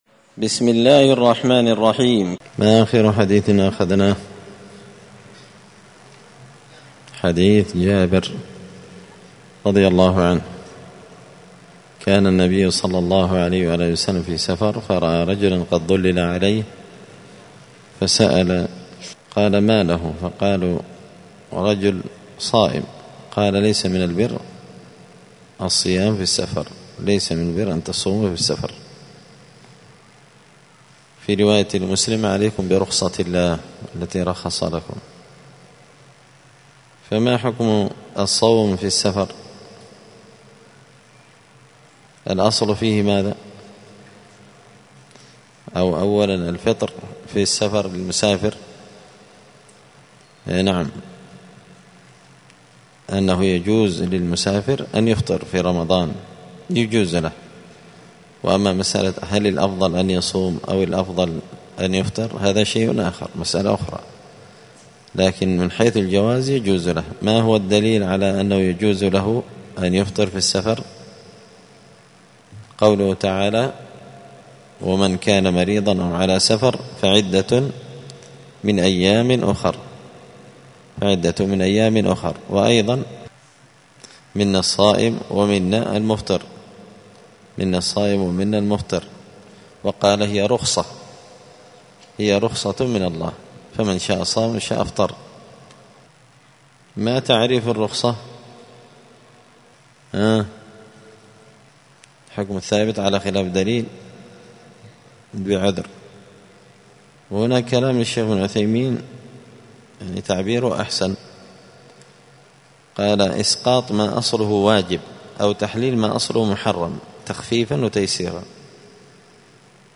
دار الحديث السلفية بمسجد الفرقان بقشن المهرة اليمن
*الدرس الخامس عشر (15) {أيهما أفضل للمسافر الصوم أم الفطر…}*